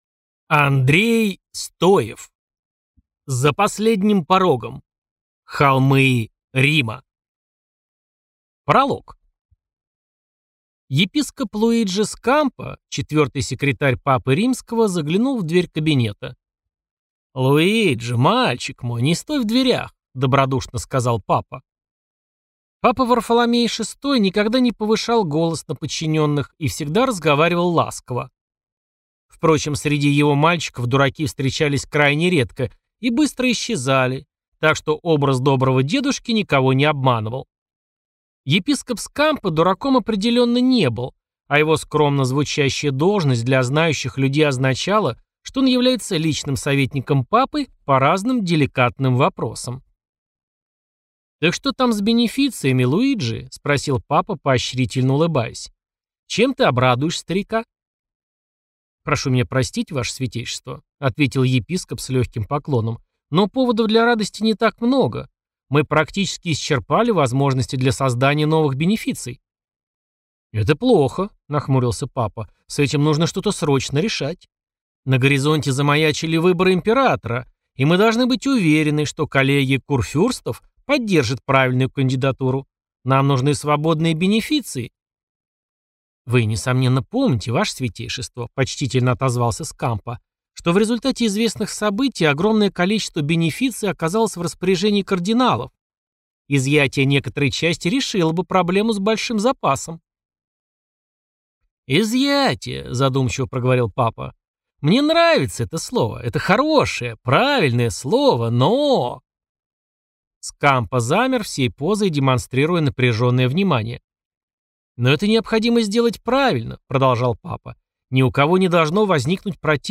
Аудиокнига За последним порогом. Холмы Рима | Библиотека аудиокниг